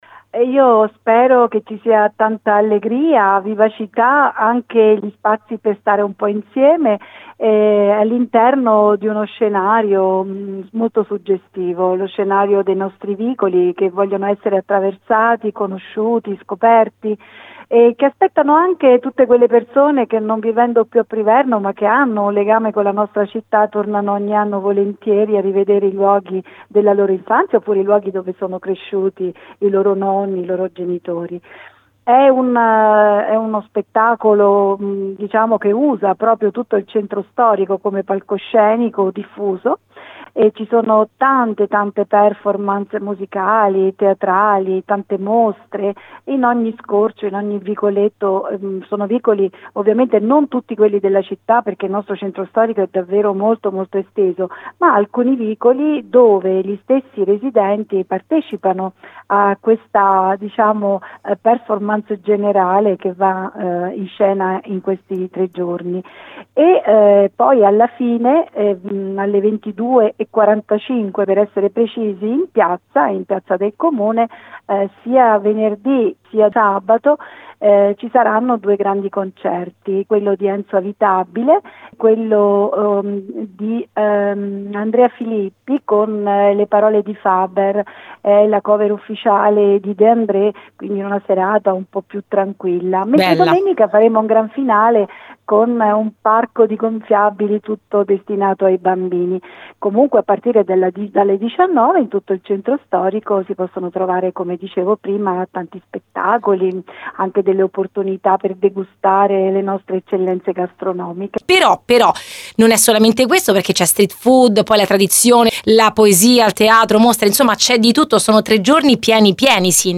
Il sindaco Anna Maria Bilancia
svicolando_sindaco.mp3